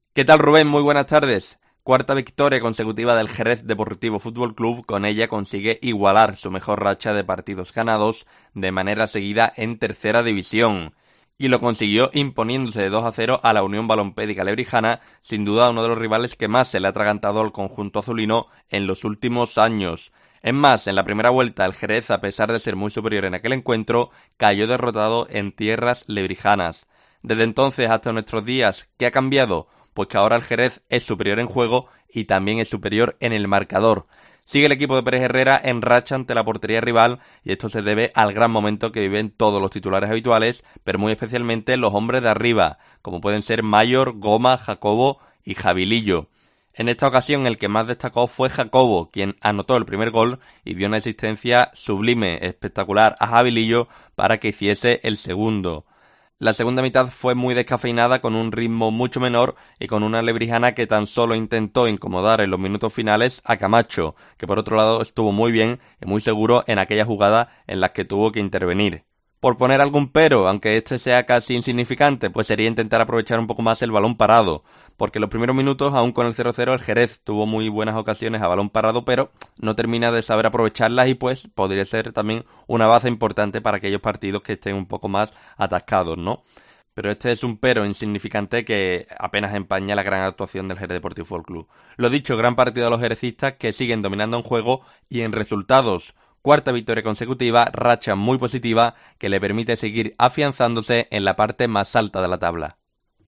La crónica de la victoria del Xerez DFC